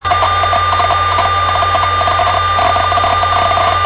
けたたましく警告音を上げる
今度の音はかなり大きい音です。けたたましい「ピー」という音です。
この音はアクセスしないでそのままにしているときに突然鳴り出しました。